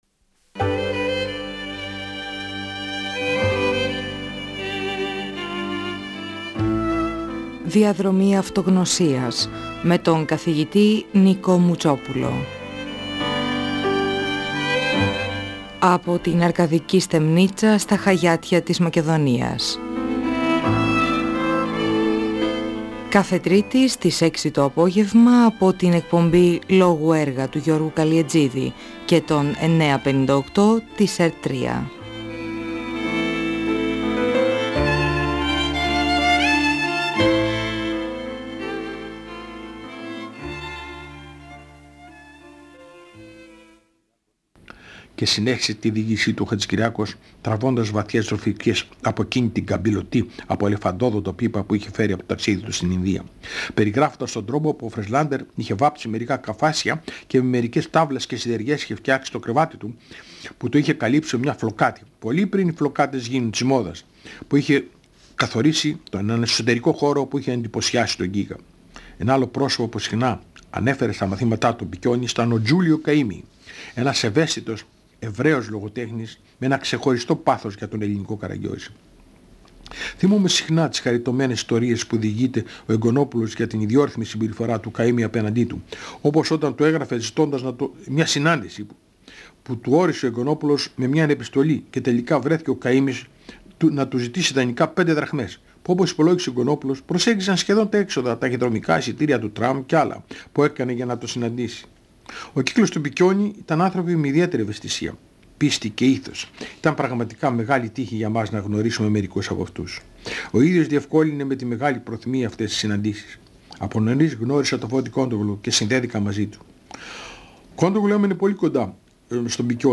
Παρουσιάζει γνώμες άλλων για τη διδασκαλία του Πικιώνη. Διαβάζει αποσπάσματα από κείμενα του Πικιώνη και μιλά για το ενδιαφέρον του για την Ελληνική Γραμματεία, για τους αγαπημένους του ποιητές και για τις συναντήσεις τους.